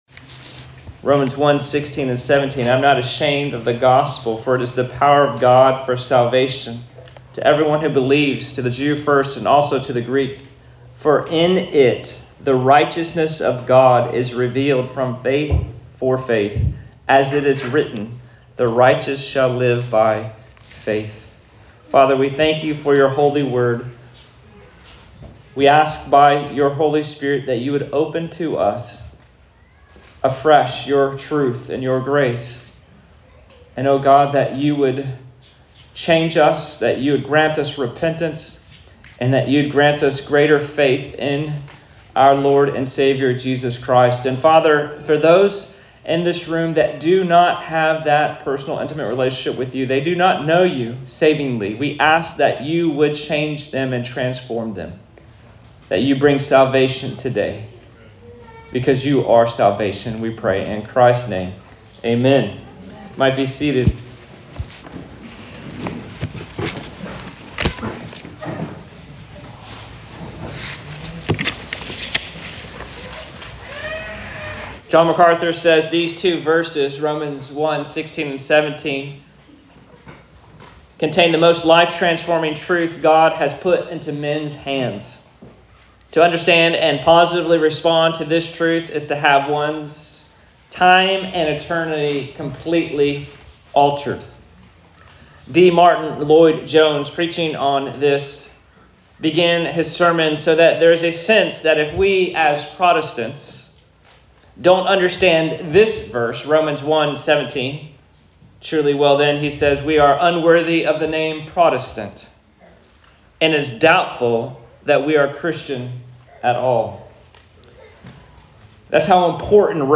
Sermons | Christ Community Church